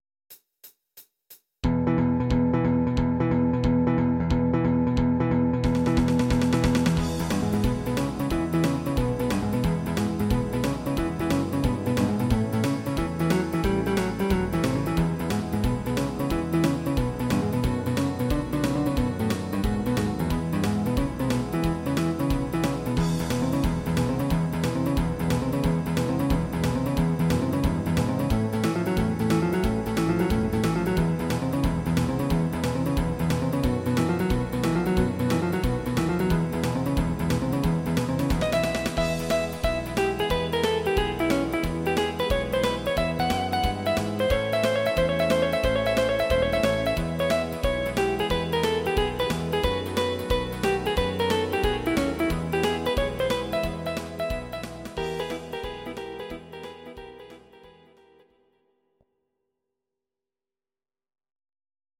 These are MP3 versions of our MIDI file catalogue.
Please note: no vocals and no karaoke included.
instr. Gitarre